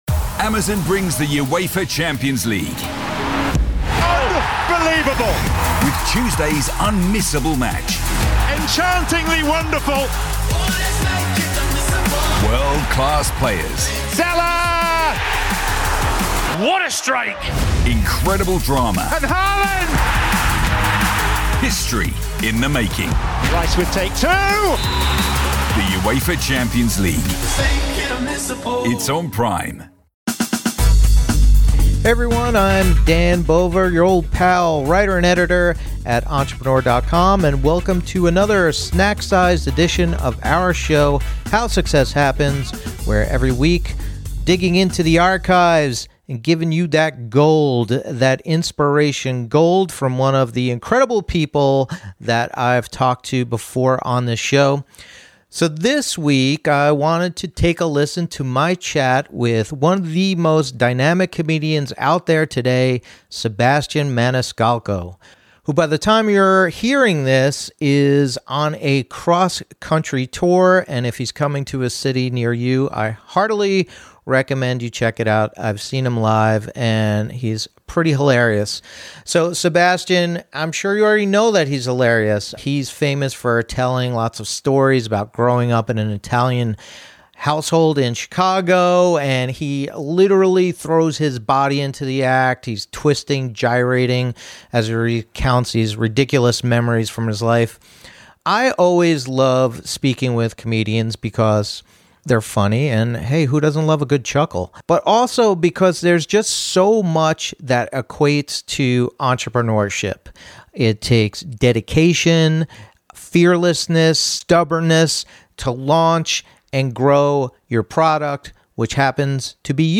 dusts off his convo with comedy powerhouse Sebastian Maniscalco